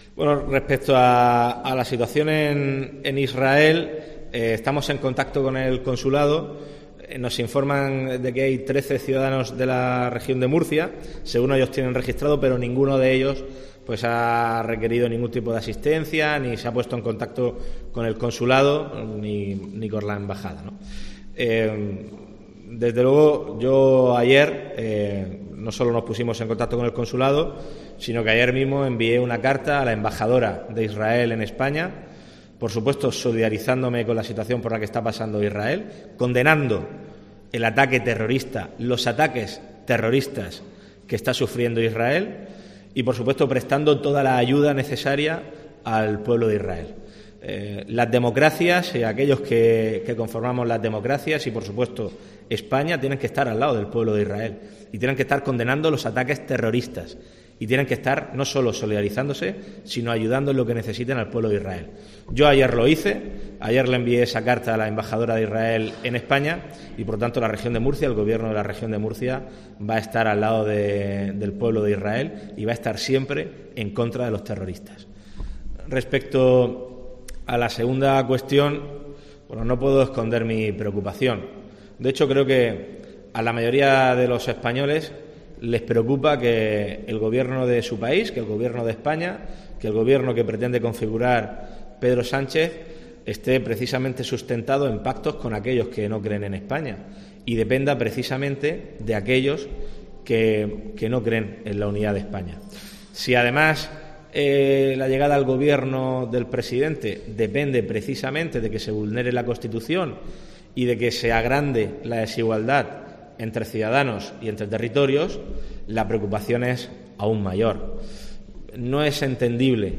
“Ninguno de ellos ha requerido asistencia y tampoco han contactado con el Consulado, ni la Embajada de Israel”, por lo que se entiende que no han requerido ayuda por el momento, ha señalado López Miras, quien ha hecho estas declaraciones en Cartagena durante un acto por el Día Mundial de la Salud Mental.